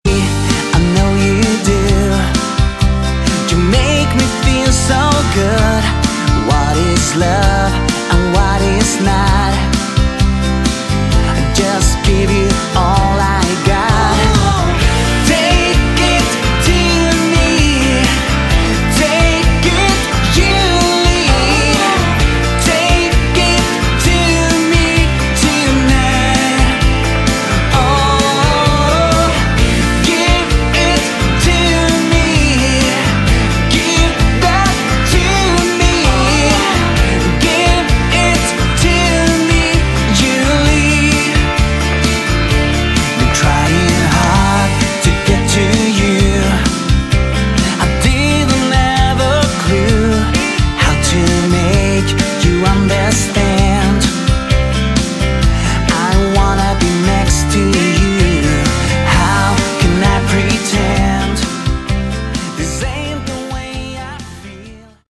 subtle Hard Rock/AOR album
genuine and original Melodic hard rock with AOR flavour